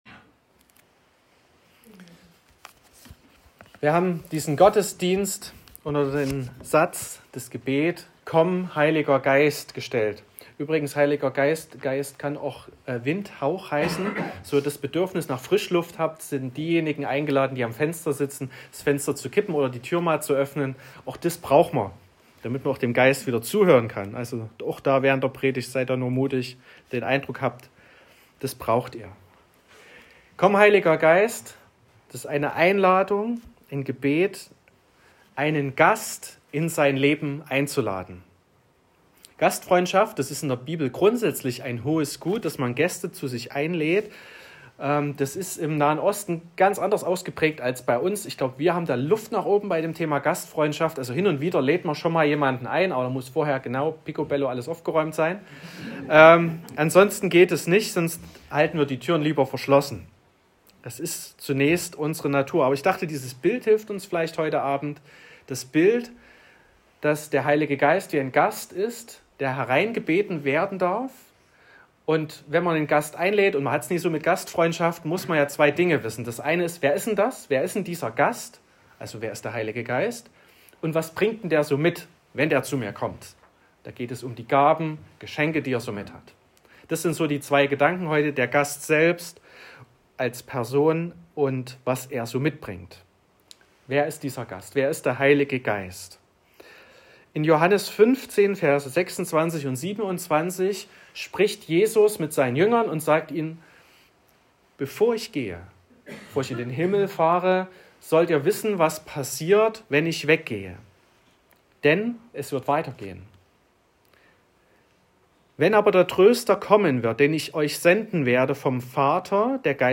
09.02.2025 – Mehr-Licht-Gottesdienst
Predigt und Aufzeichnungen
Predigt (Audio): 2025-02-09_Komm__Heiliger_Geist.m4a (9,3 MB)